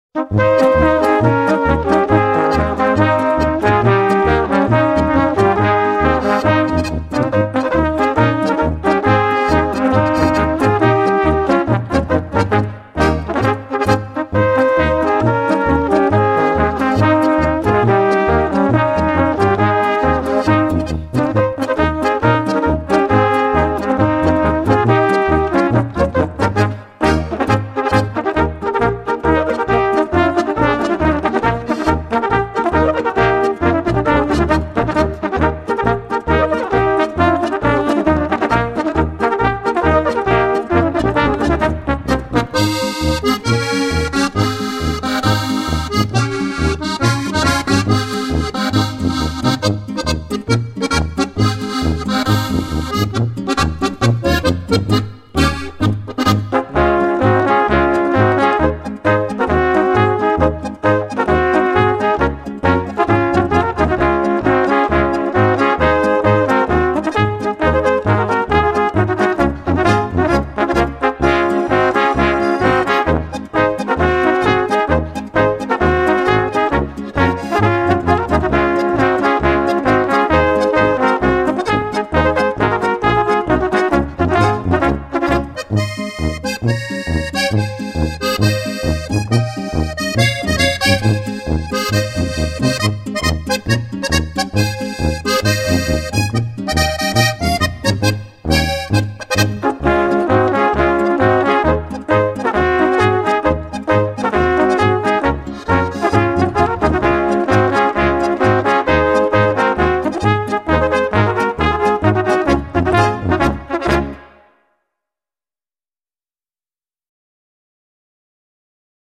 Categorie Ensembles
Bezetting Tanzlmusi
Aanvullende informatie/inhoud Flotte Polka